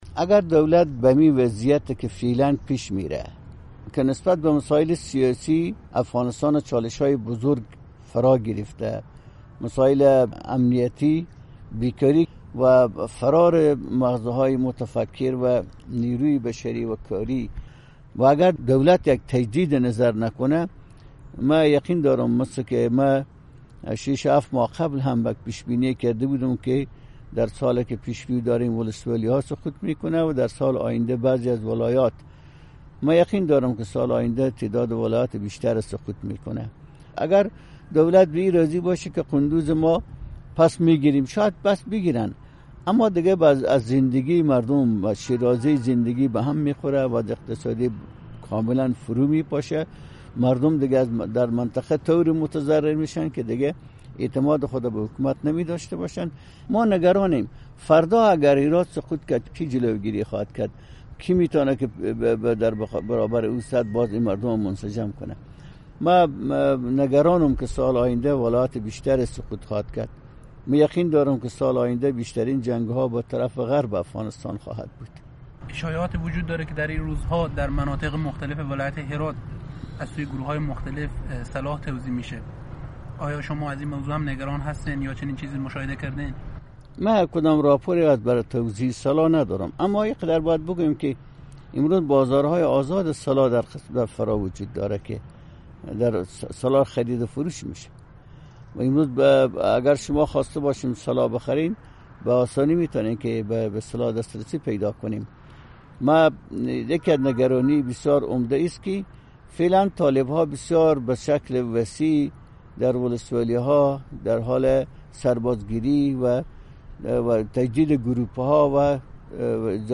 مصاحبه ها